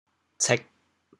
槭 部首拼音 部首 木 总笔划 15 部外笔划 11 普通话 qì sè 潮州发音 潮州 cêg4 文 潮阳 cêg4 文 澄海 cêg4 文 揭阳 cêg4 文 饶平 cêg4 文 汕头 cêg4 文 中文解释 潮州 cêg4 文 对应普通话: qì 落叶小乔木，树干平滑，叶对生，掌状分裂，秋季变为红色或黄色。